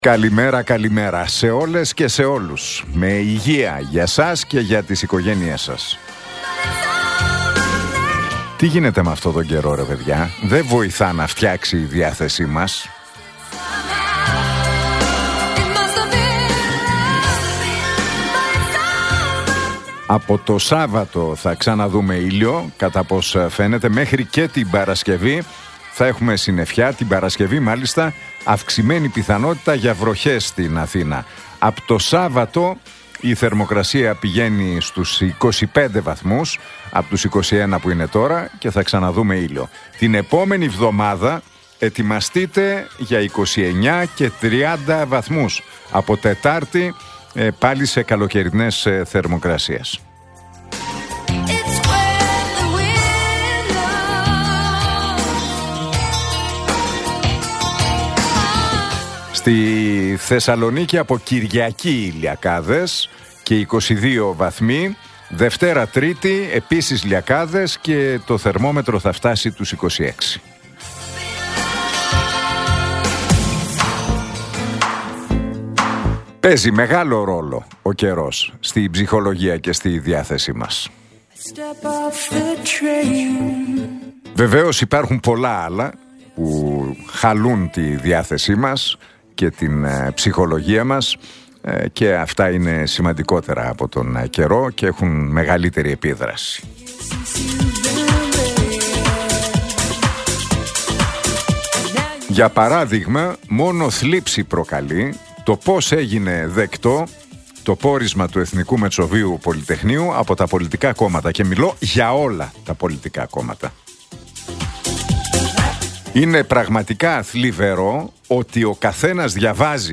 Ακούστε το σχόλιο του Νίκου Χατζηνικολάου στον ραδιοφωνικό σταθμό Realfm 97,8, την Τετάρτη 14 Μαΐου 2025.